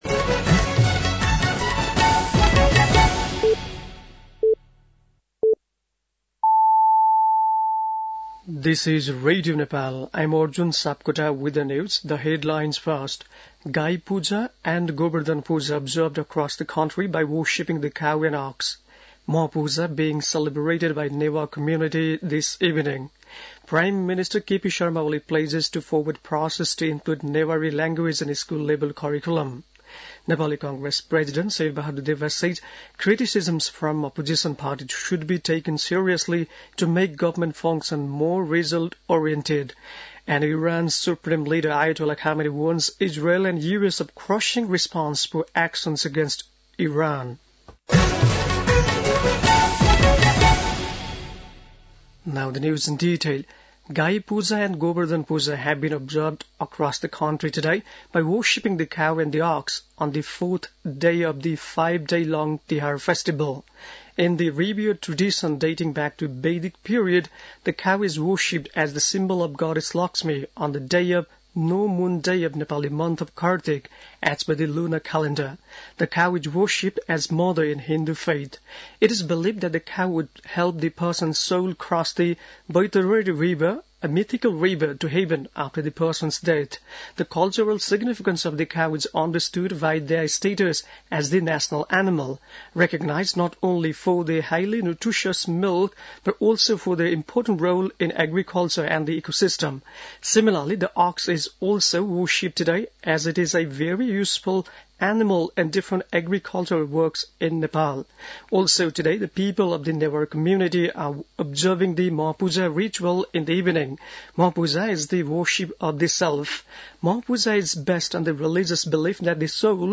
बेलुकी ८ बजेको अङ्ग्रेजी समाचार : १८ कार्तिक , २०८१
8-pm-english-news-7-17.mp3